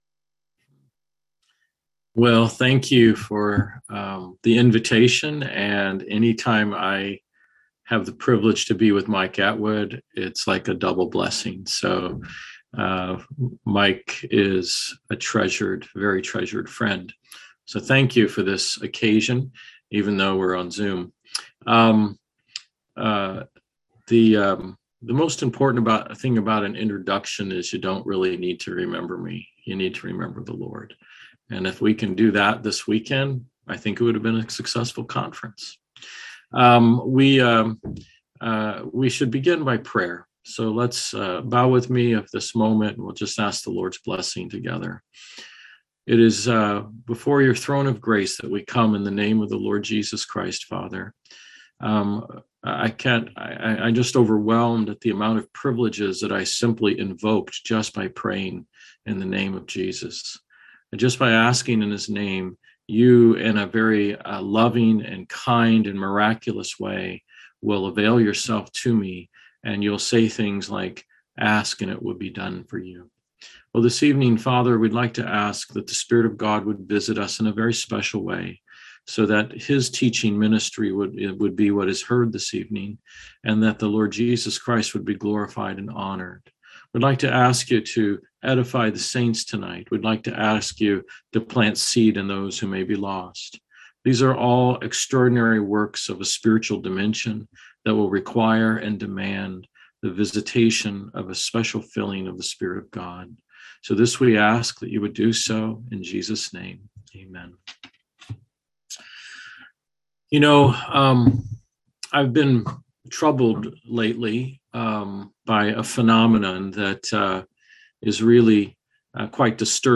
Series: Easter Conference
Service Type: Seminar Topics: Attributes of God